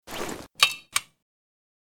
kiparis_draw.ogg